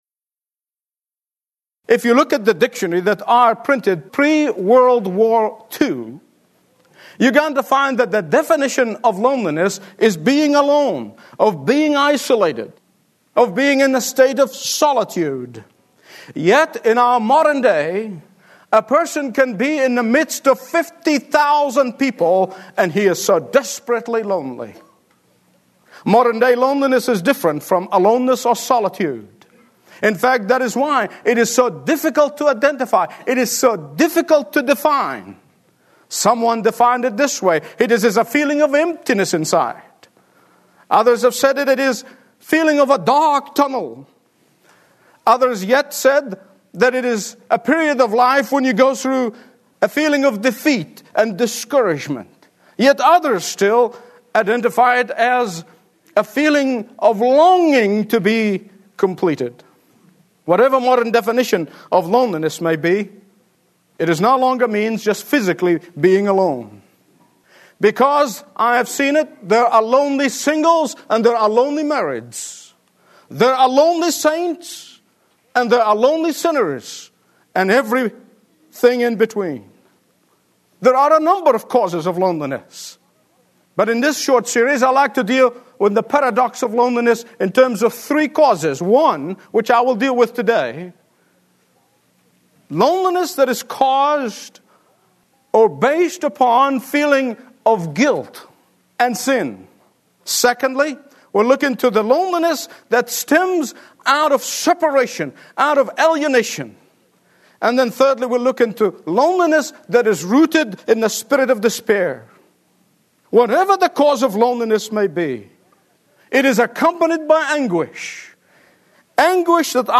Stream Expository Bible Teaching & Understand the Bible Like Never Before